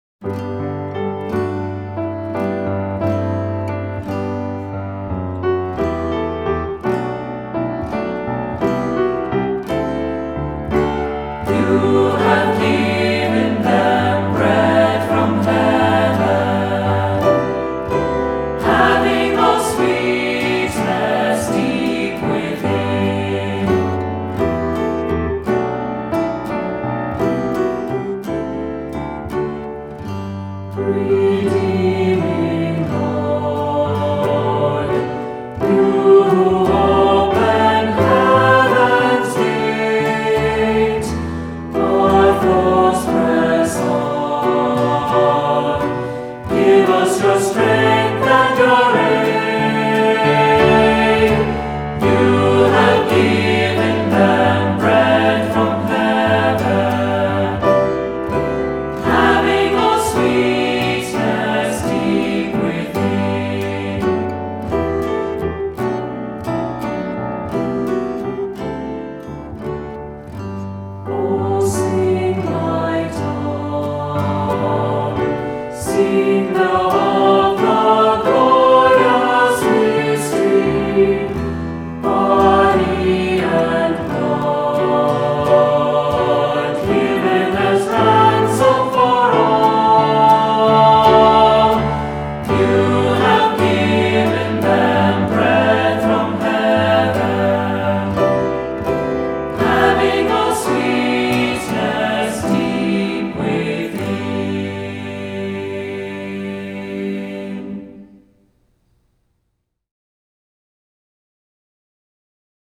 Voicing: Cantor, assembly,SAB